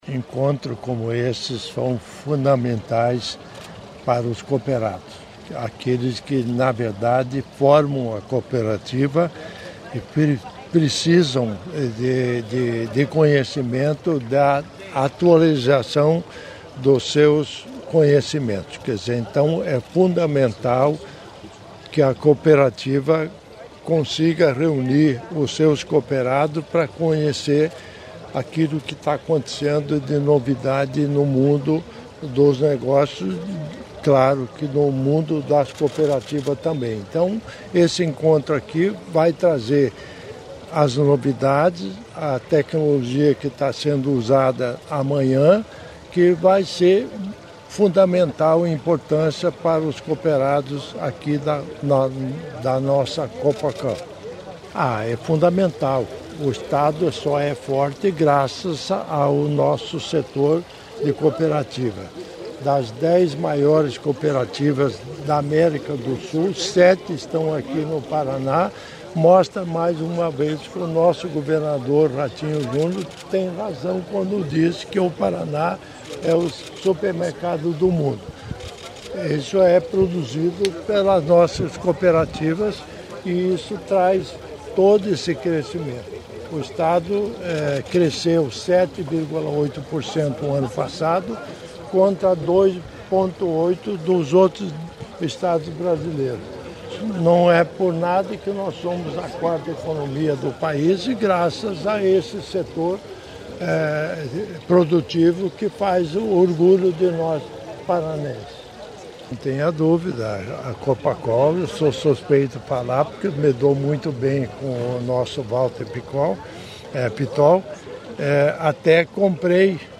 Sonora do governador em exercício Darci Piana na abertura do Copacol Agro